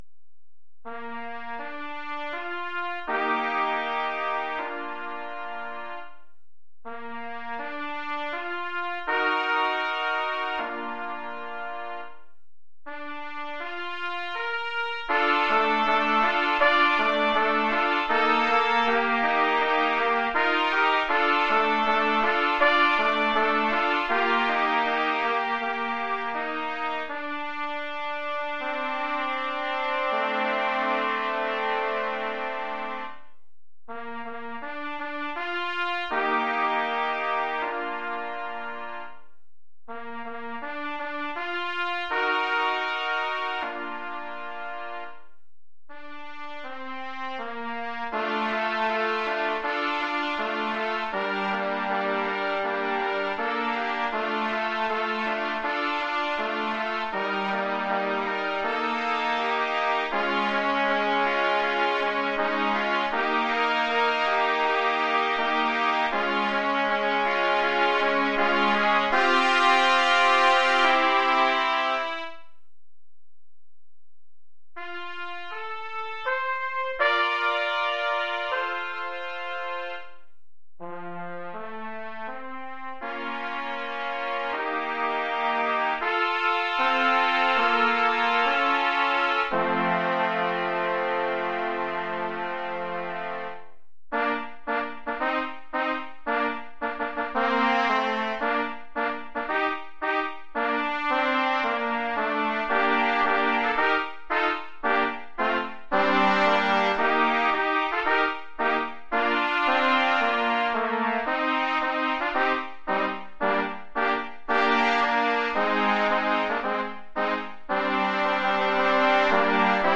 Oeuvre pour trio de trompettes
(trompettes en sib 1, 2 et 3).